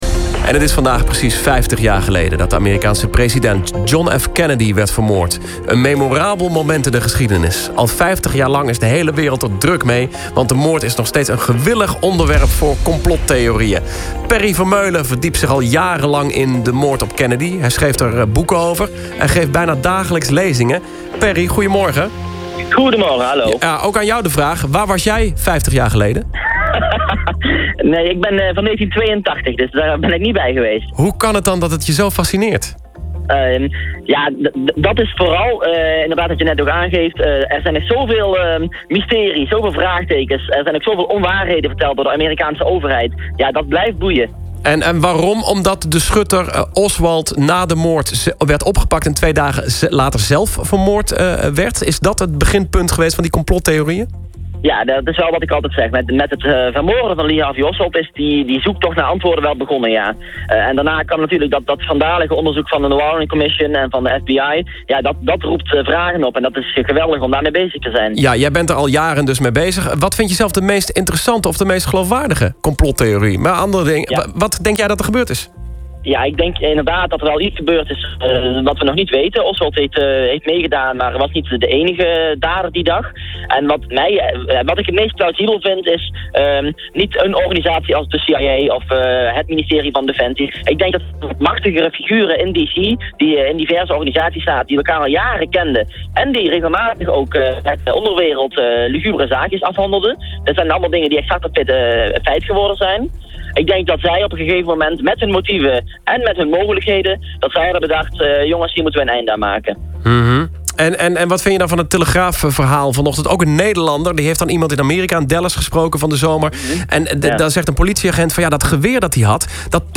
Ik werd geïnterviewd voor diverse radio-programma’s.